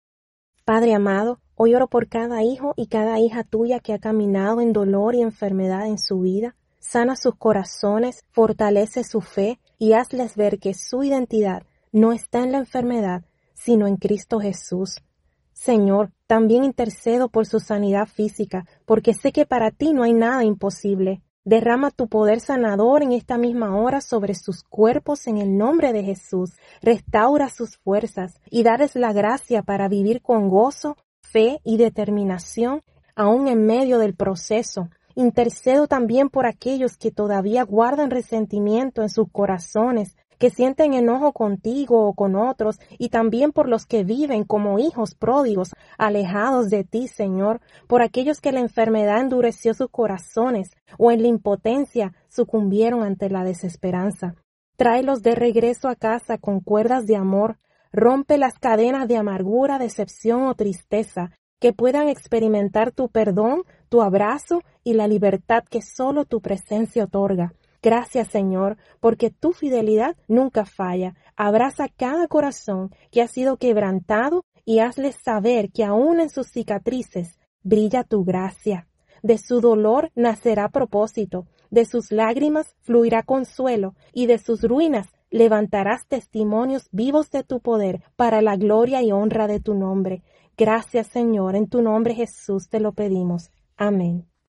Oración